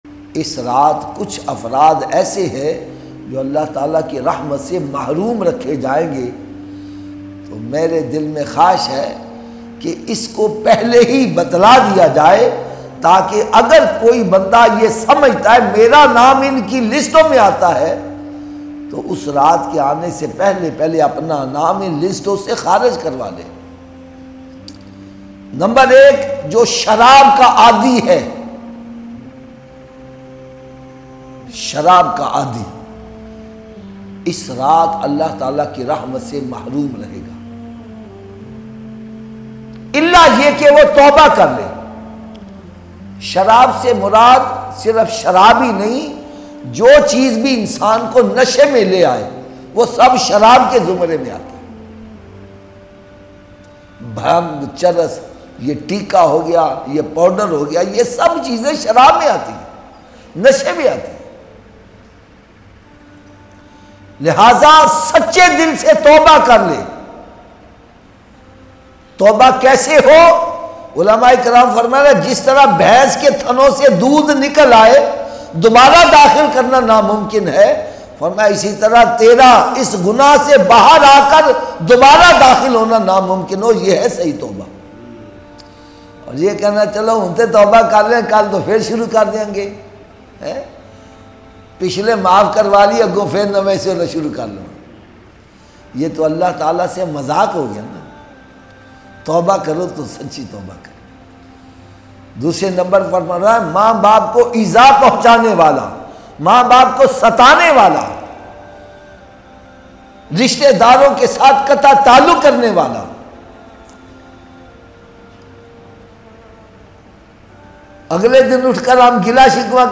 Shab e Barat Ki Fazilat Lattest Bayan MP3 Download